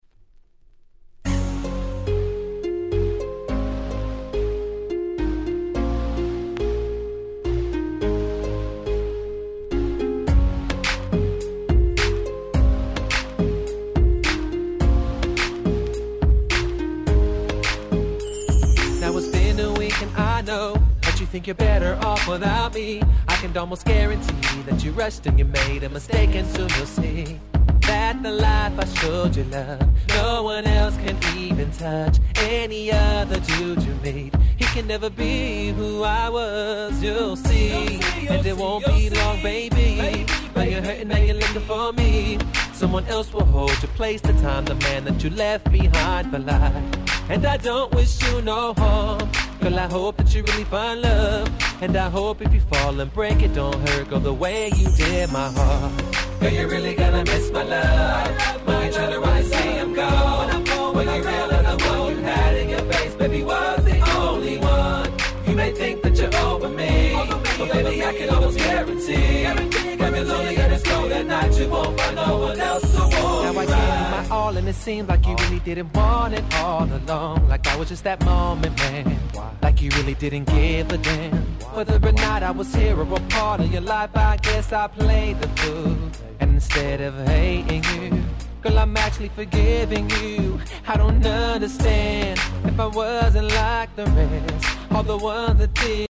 09' Nice R&B Compilation !!